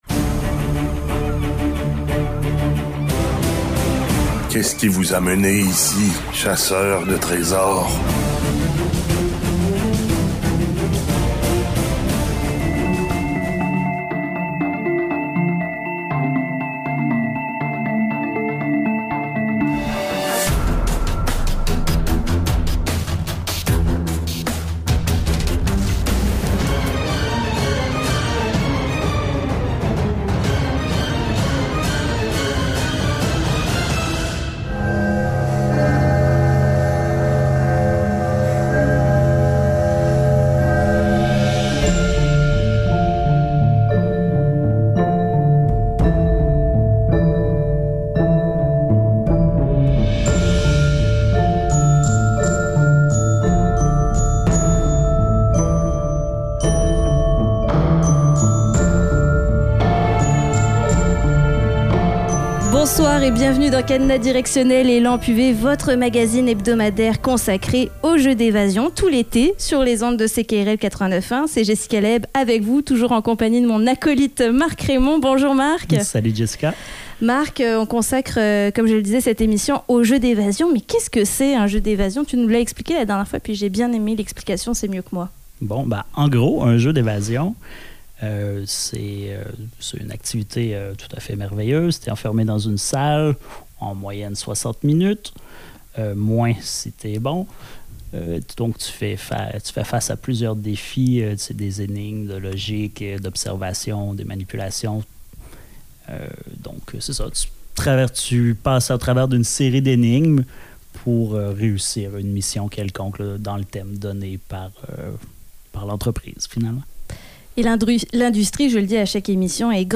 Un peu comme pour le nombre de clowns qu’on peut faire entrer dans une petite voiture, on se demandait combien de blogueurs de jeux d’évasion on pouvait réunir autour du micro. Tu as manqué ce moment de radio ?